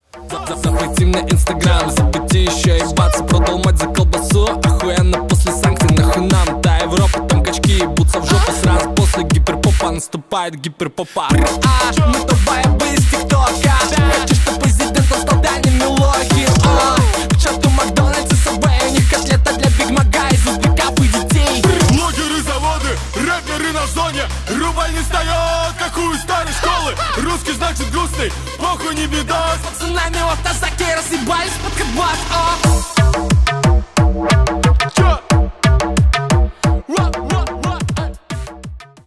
Рэп и Хип Хоп
громкие # ритмичные